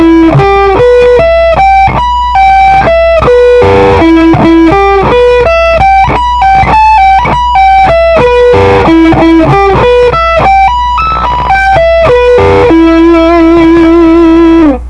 slow wav of the Eminor part.
slowe.wav